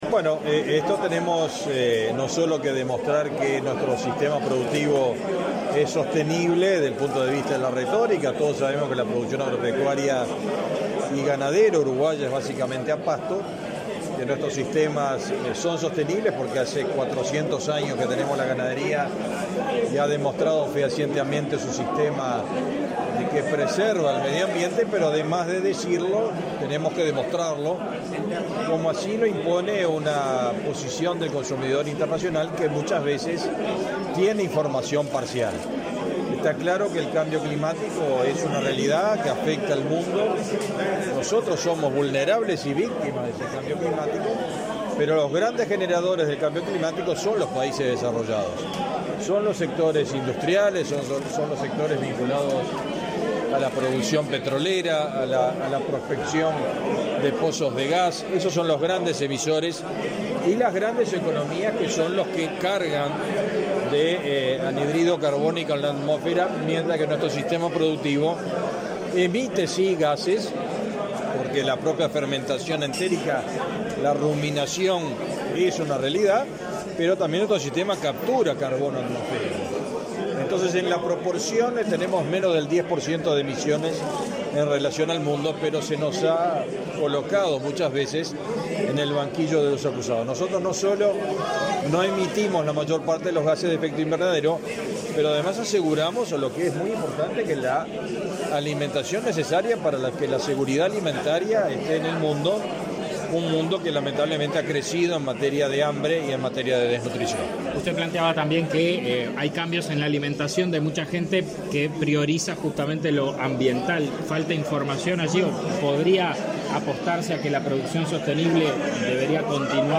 Declaraciones a la prensa del ministro de Ganadería, Agricultura y Pesca, Fernando Mattos
Tras participar en la presentación de la Huella Ambiental de la Ganadería del Uruguay, este 11 de octubre, el ministro de Ganadería, Agricultura y